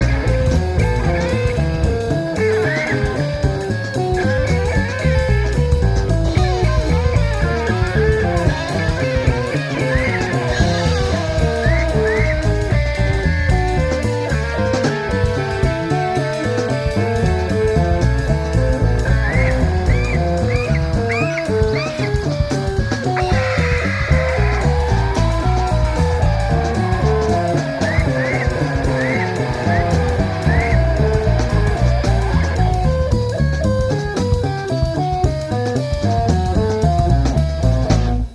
rock instrumental - math